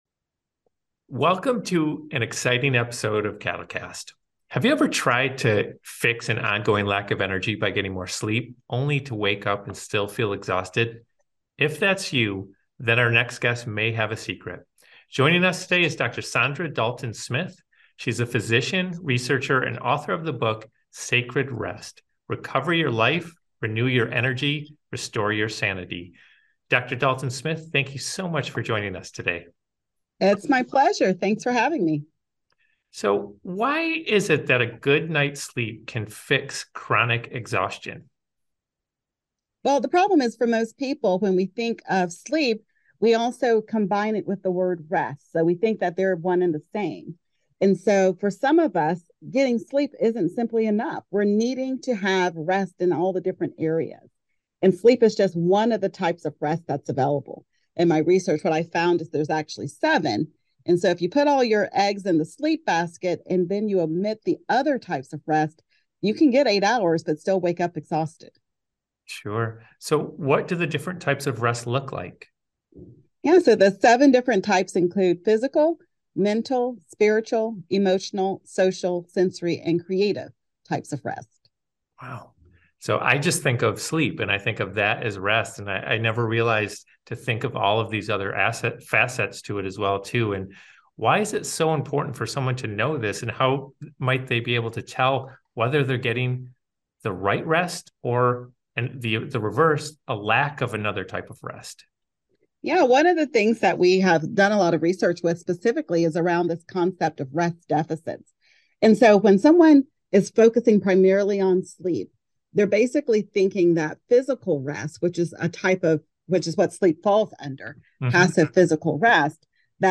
This special episode of CADL Cast is a video interview!